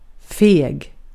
Ääntäminen
IPA : [ˈtʃɪk.ən]